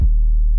tm 808.wav